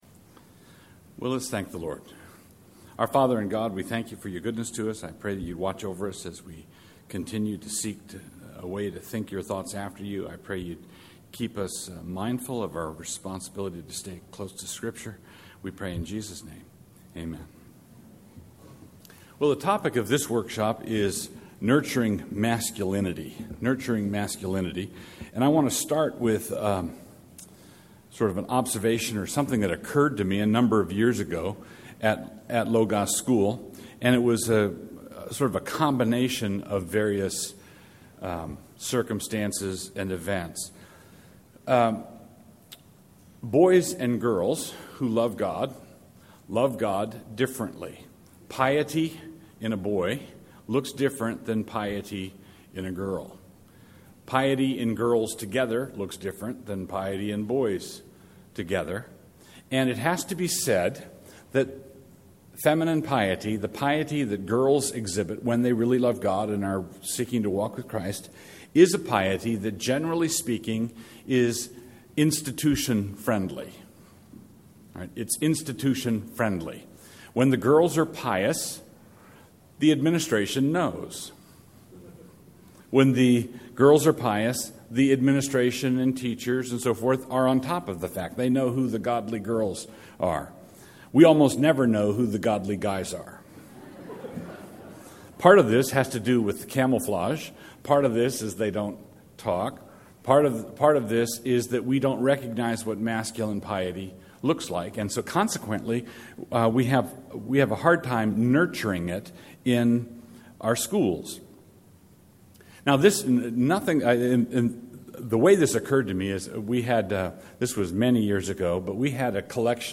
2012 Workshop Talk | 1:00:20 | All Grade Levels, Virtue, Character, Discipline